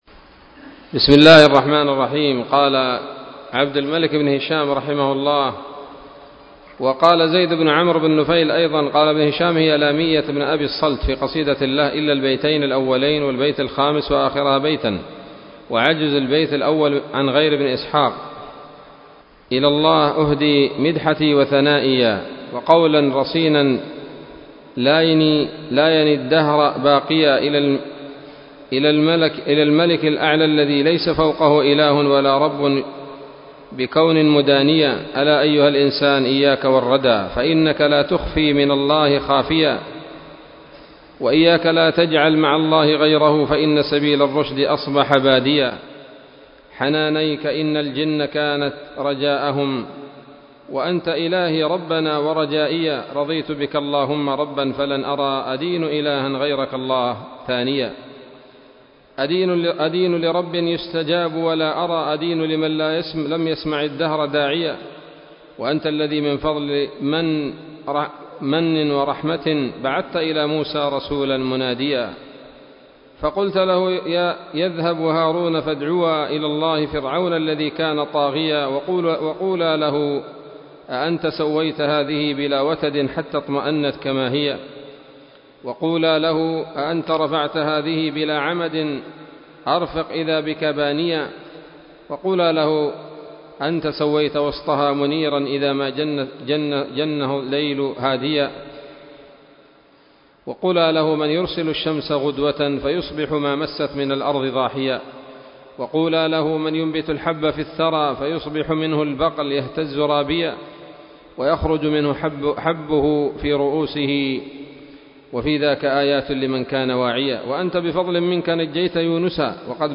الدرس الحادي والعشرون من التعليق على كتاب السيرة النبوية لابن هشام